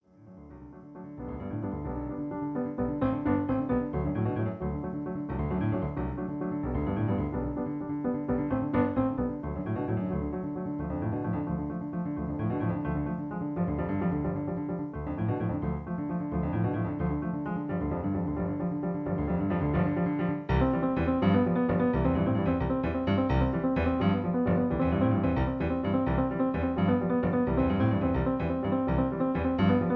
Original Key (C)